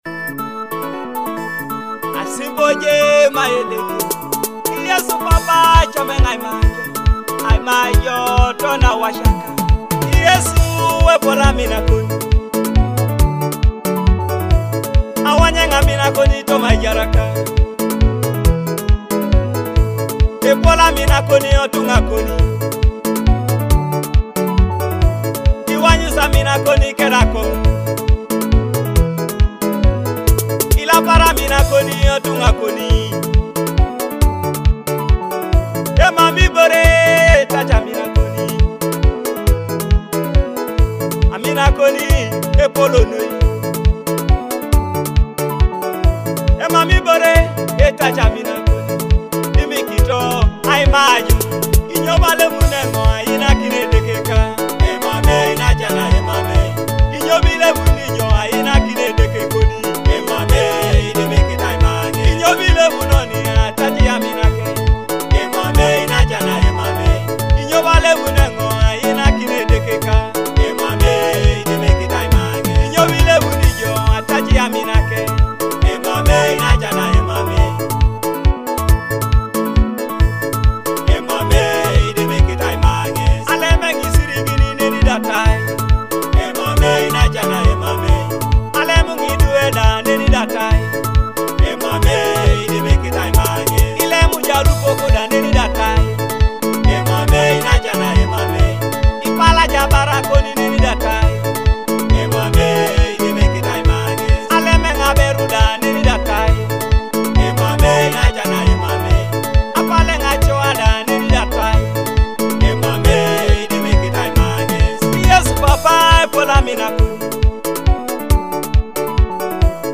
soul-stirring gospel hit